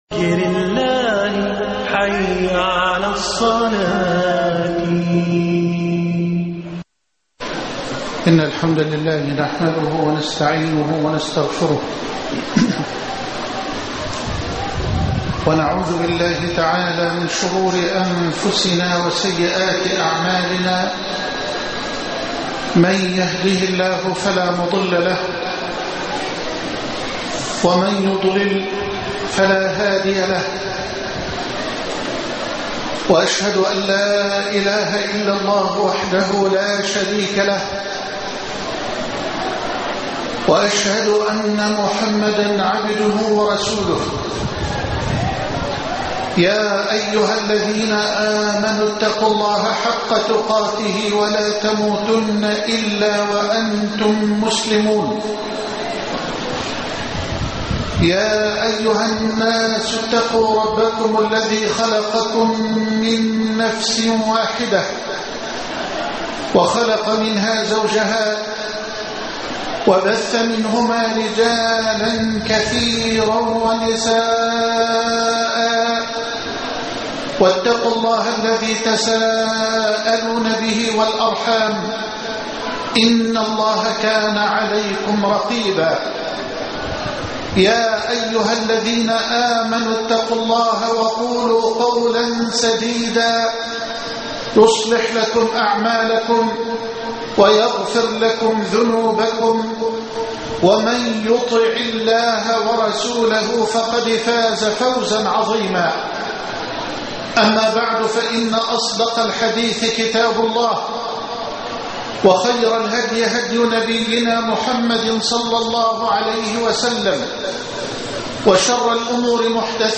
ربانية الشريعة (1/6/2012) خطب الجمعة - فضيلة الشيخ محمد حسان